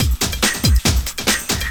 04 LOOP07 -R.wav